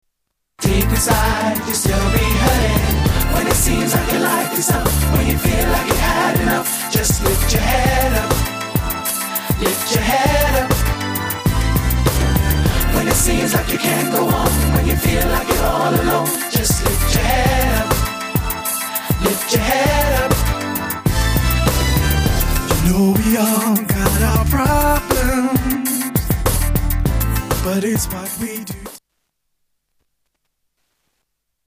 R&B Single